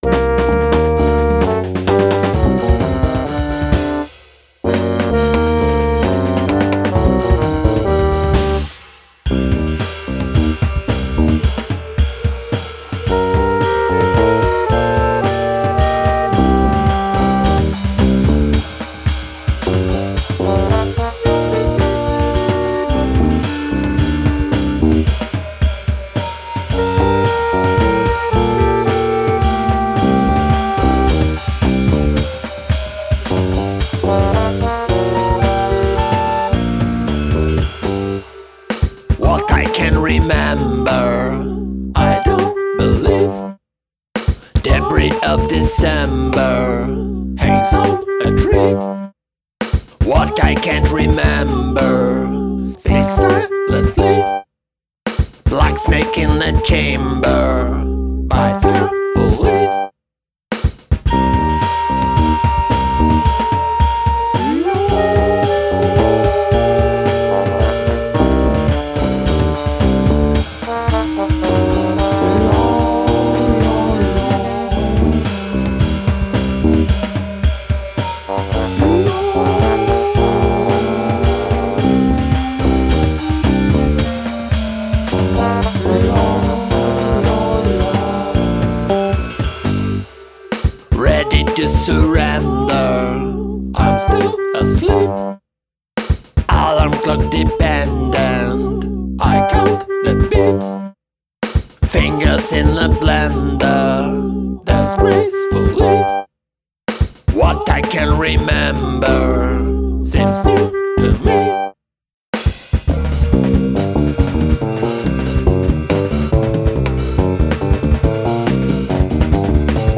Two hippie songs for yuppie folks.